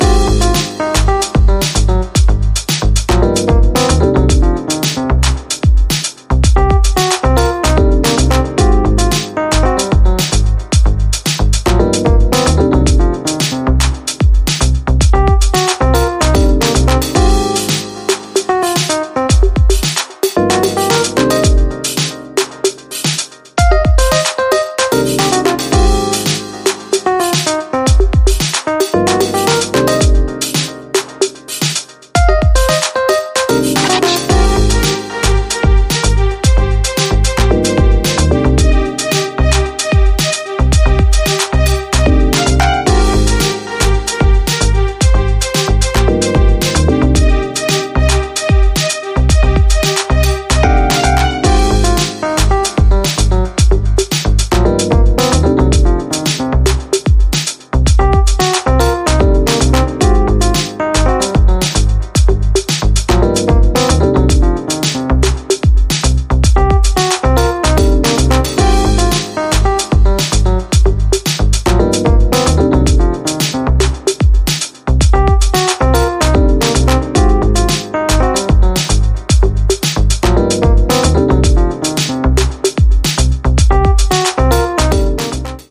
ここでも、今までのように程よくエレクトリックな音色を用いながらモダンでウォームなディープ・ハウスを展開。